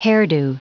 Prononciation du mot : hairdo
hairdo.wav